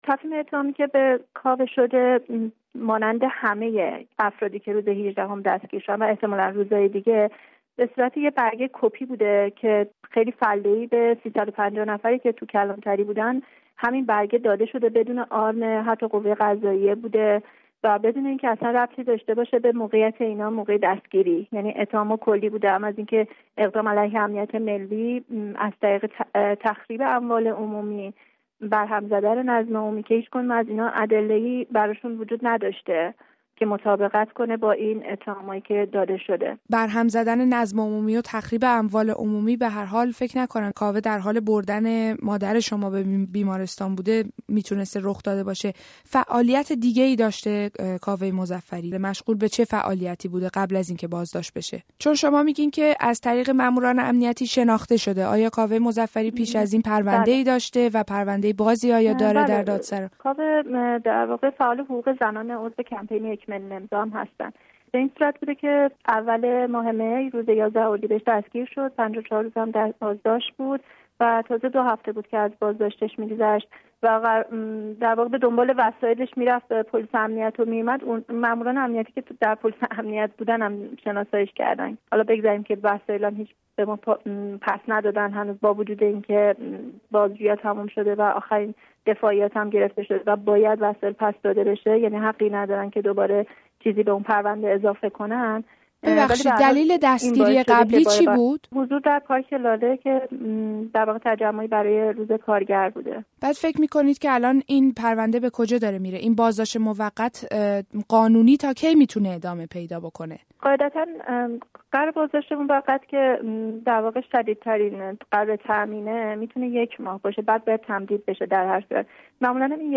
«تفهیم اتهام فله‌ای»-گفت‌وگو